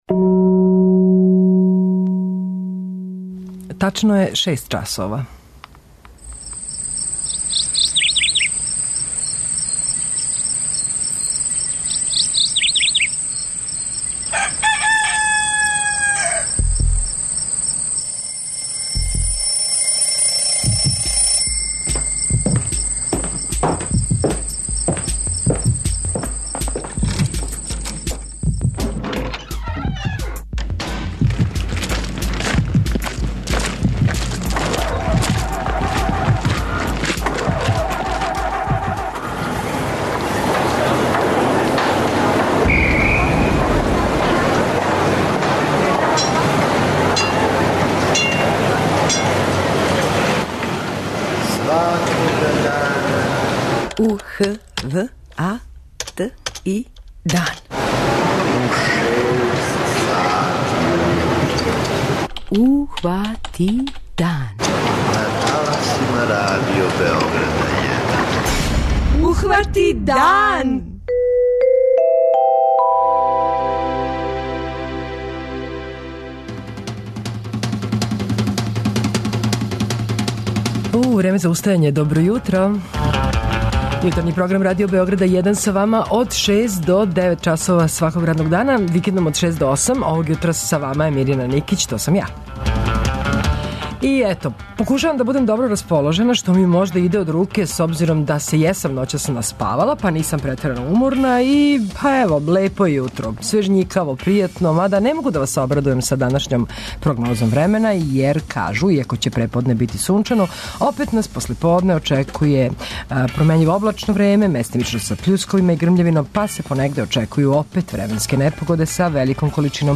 Петком је и квиз Јутарњег програма, овог пута специфичан по својој актуелности.